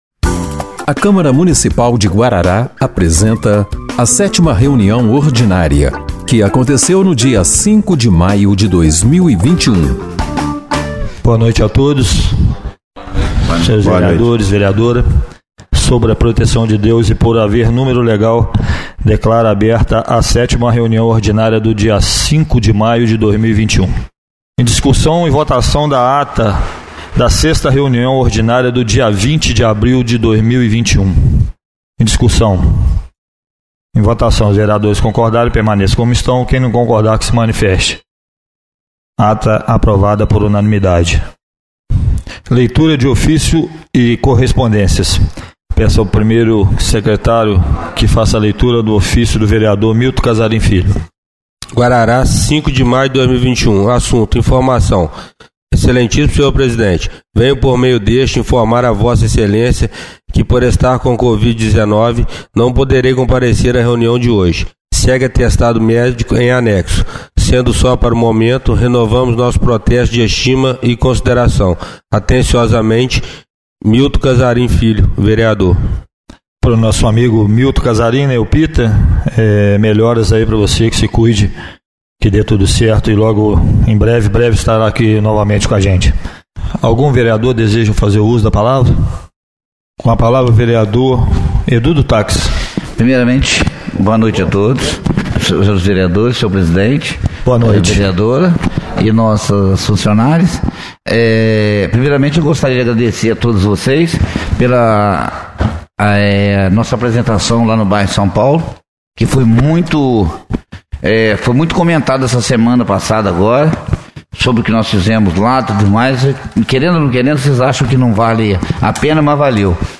7ª Reunião Ordinária de 05/05/2021